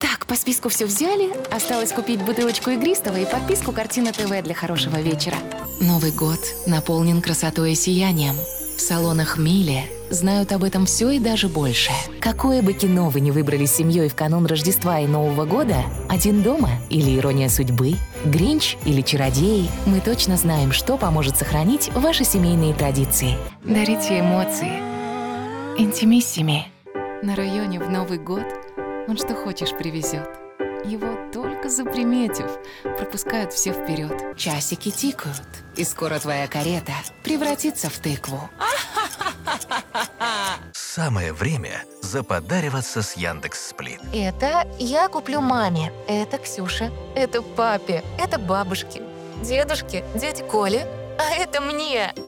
14 лет Глубокий, насыщенный голос. Универсальный диктор.